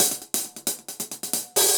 UHH_AcoustiHatC_135-03.wav